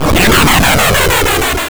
bfxr_PlayerShootdown.wav